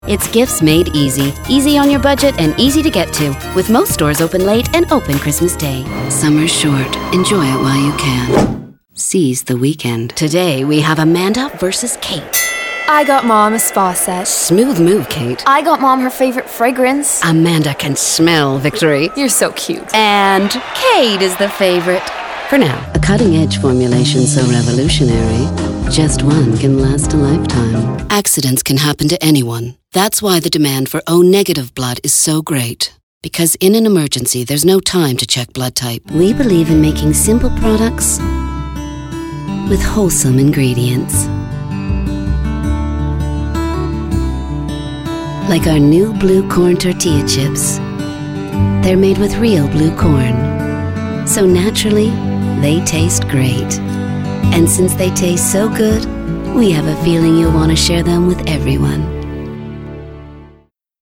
Sprechprobe: Werbung (Muttersprache):
Rich, textured, sultry and dynamic reads